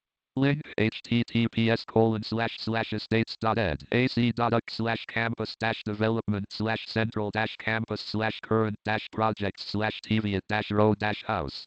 They are difficult to interpret and screen readers read them character by character, which can be slow, frustrating and unclear.
This included using JAWS recordings to compare how raw URLs and descriptive links are experienced by a screen reader user.